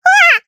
Taily-Vox_Damage_jp_01.wav